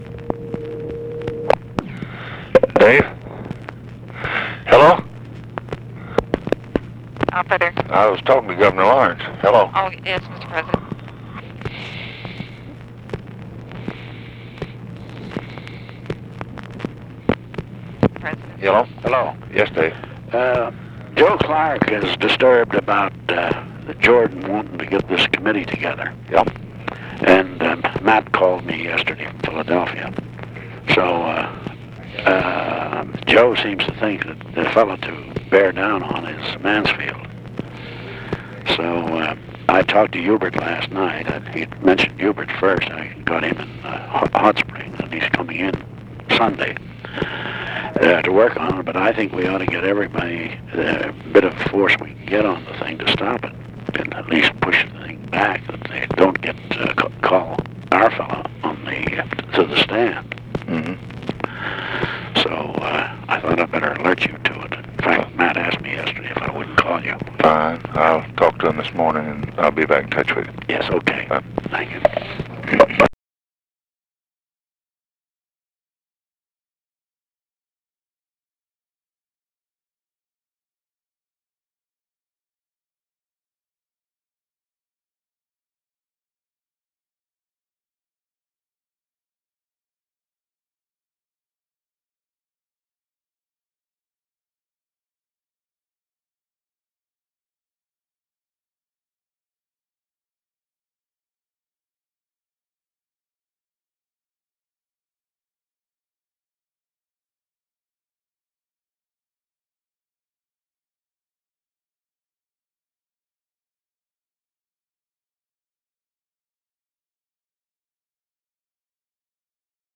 Conversation with DAVID LAWRENCE and TELEPHONE OPERATOR, September 18, 1964
Secret White House Tapes